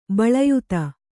♪ baḷayuta